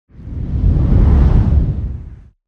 دانلود آهنگ باد 45 از افکت صوتی طبیعت و محیط
دانلود صدای باد 45 از ساعد نیوز با لینک مستقیم و کیفیت بالا
جلوه های صوتی